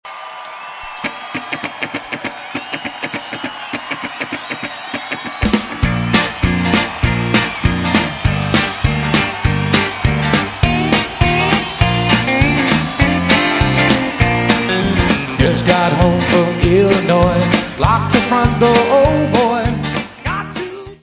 Área:  Pop / Rock